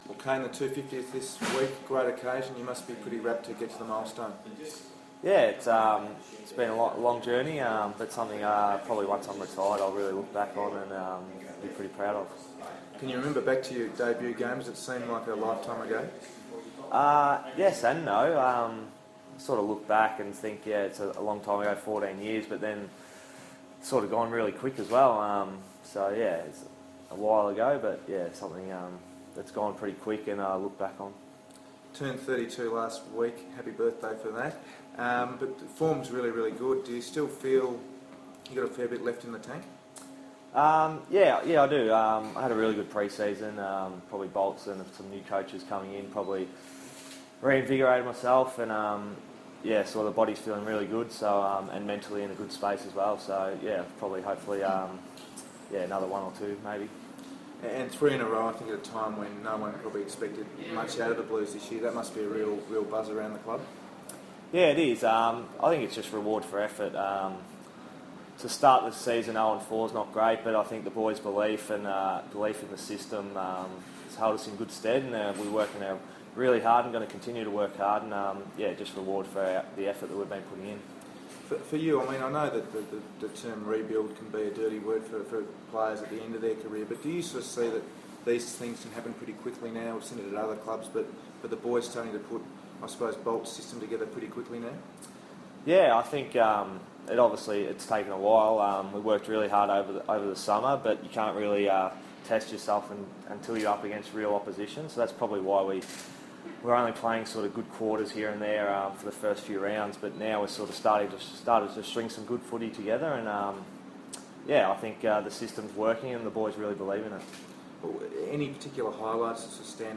Carlton defender Kade Simpson speaks to the media at the Eynesbury Golf Club ahead of his 250th AFL match.